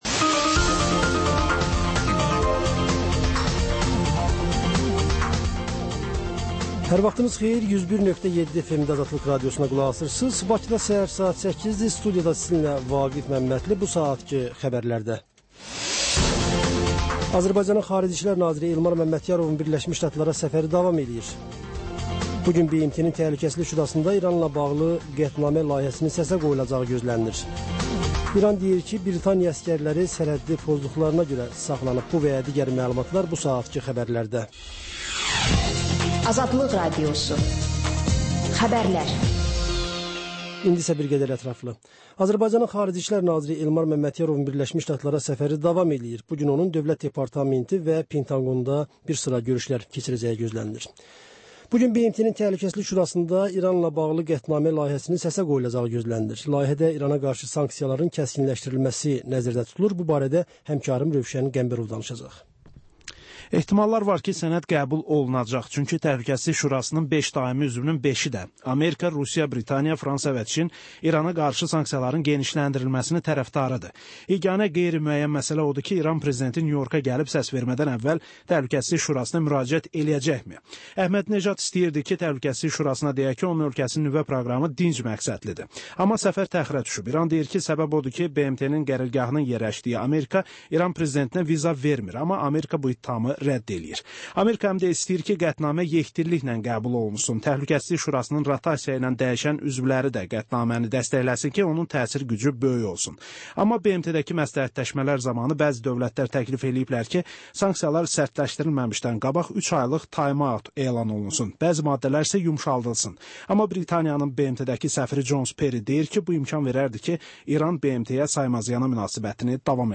Xəbərlər, ardınca XÜSUSİ REPORTAJ rubrikası: Ölkənin ictimai-siyasi həyatına dair müxbir araşdırmaları. Sonda isə TANINMIŞLAR verilişi: Ölkənin tanınmış simalarıyla söhbət